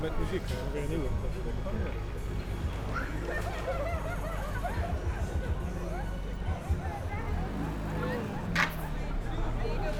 Environmental
Streetsounds
Noisepollution